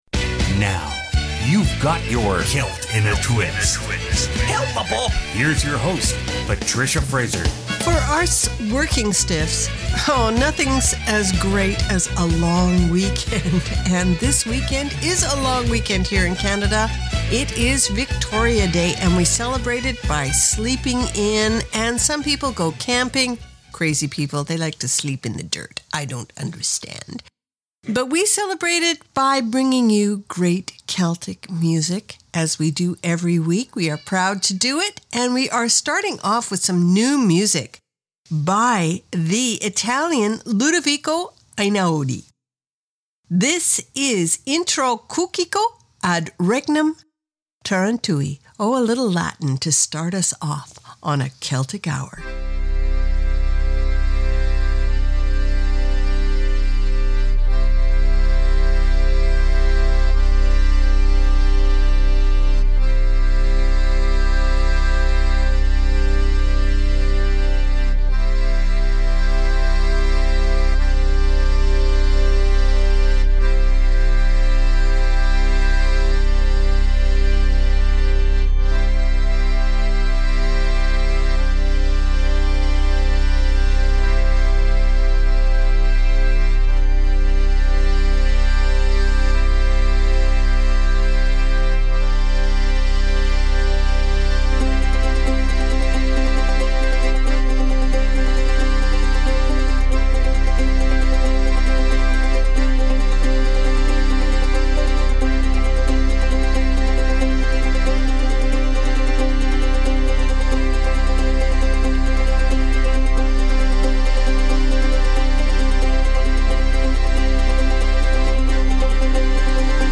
Canada's Contemporary Celtic Radio Hour
File Information Listen (h:mm:ss) 0:59:46 Celt In A Twist May 17 2015 Download (9) Celt_In_A_Twist_May17_2015.mp3 71,740k 0kbps Stereo Comments: Celtish fr. Italy, Scandinavia, India + the usual suspects.